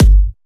VDE 130BPM Change Kick.wav